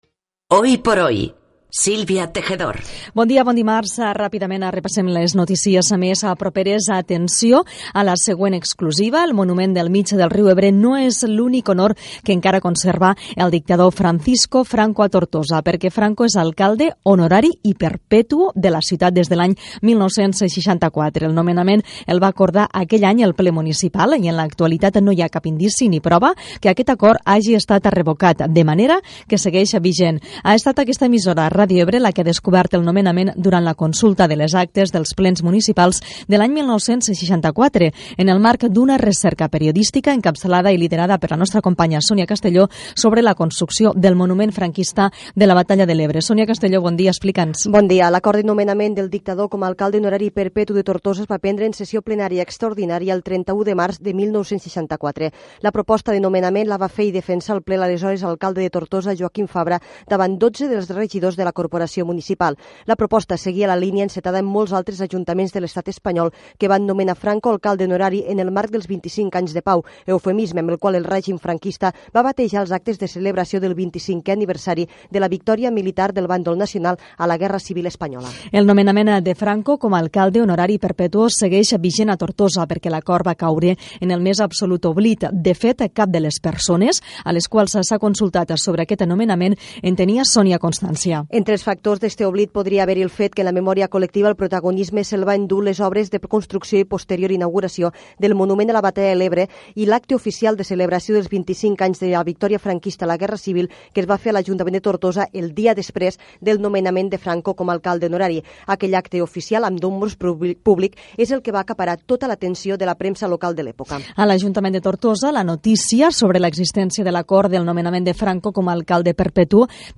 Indicatiu del programa, el general Francisco Franco encara és alcalde honorari i perpetu de Tortosa, hora, publicitat, activistes en defensa dels animals agredides, Hospital de Mora, esports i comiat
Info-entreteniment
FM